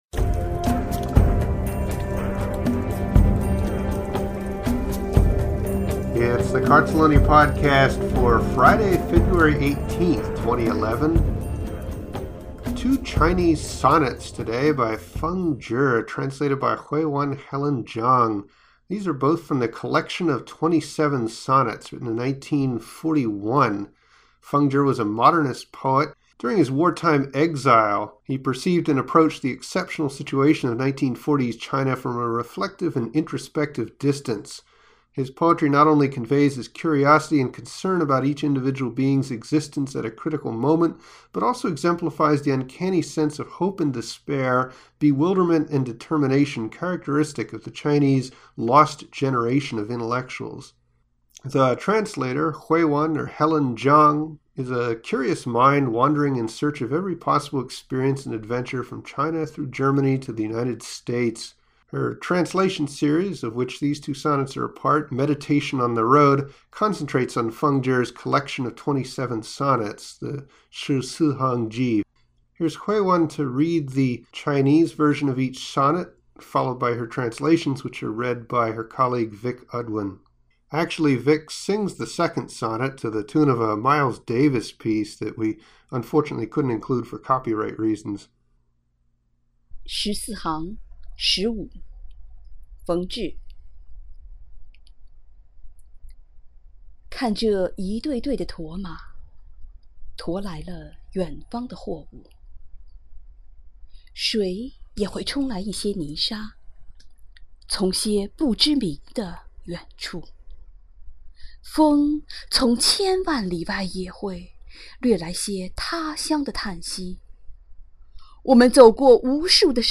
English reading